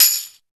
BIG125TAMB-L.wav